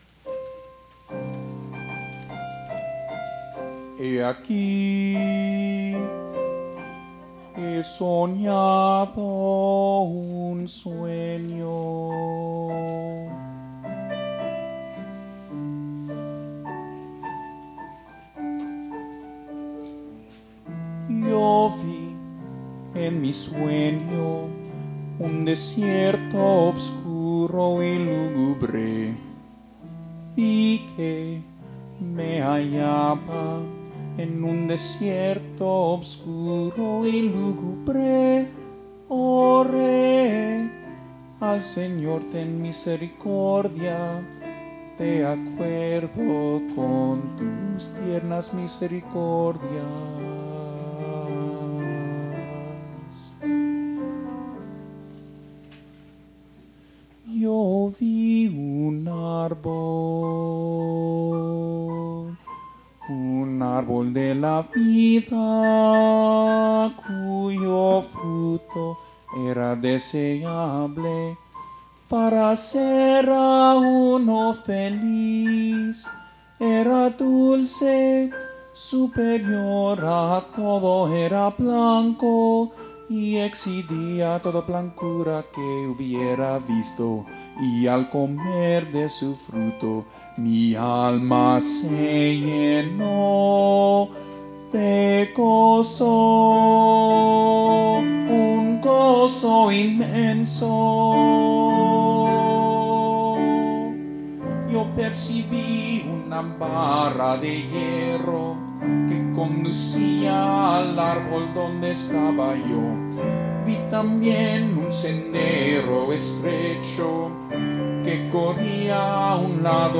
Bass, SATB Choir, and Piano